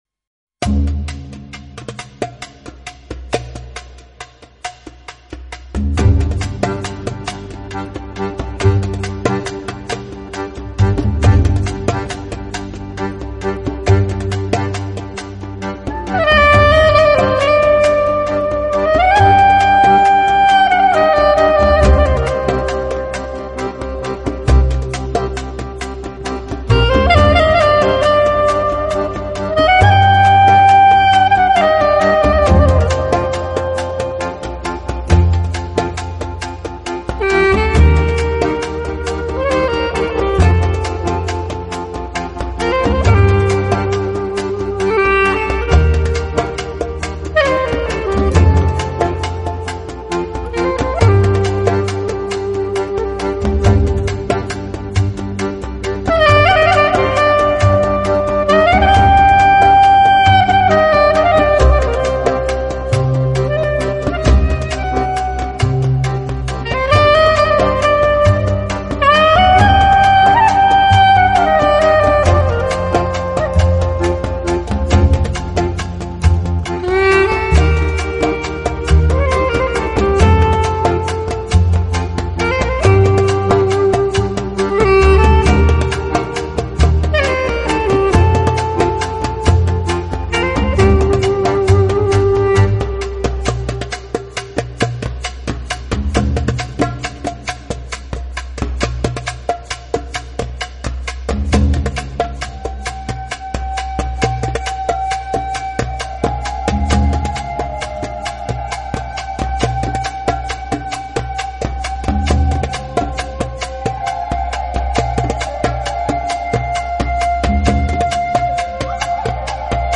风格:Jazz / World / Tango / Fusion / Ethnic
它，配上超重低音的牛筋、浪漫的手风琴和感性的萨斯风，每张专辑均德国顶级录音室录音。
(萨克思, 单簧管, 曼陀铃, 电颤琴及其它乐器) 表演感情丰富，并擅长即兴演奏
(吉他及其它乐器)，娴熟的弗拉明戈演奏技巧
(手风琴，钢琴及其它乐器) 忧郁的嗓音充满魔力，经常让他的乐器只发出呼吸般的声音。
(贝司, 打击乐器及其它乐器) 节奏感极佳，他的贝司时常被用作打击乐器。